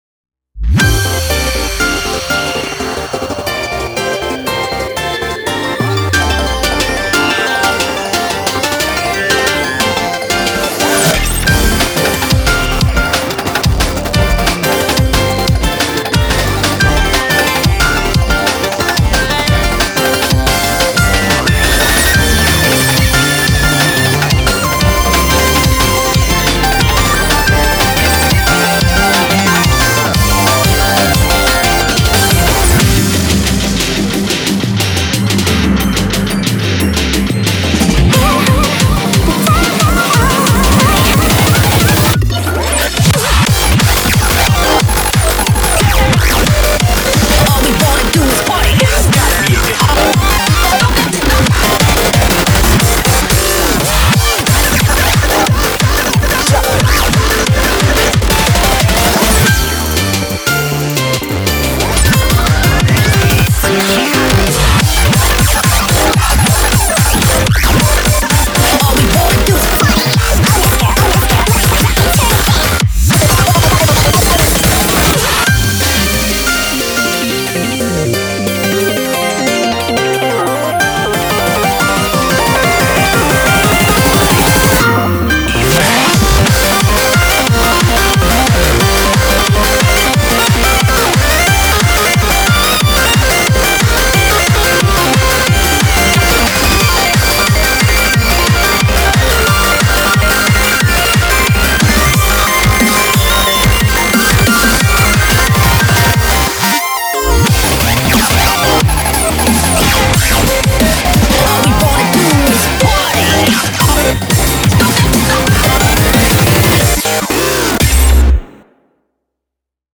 BPM90-180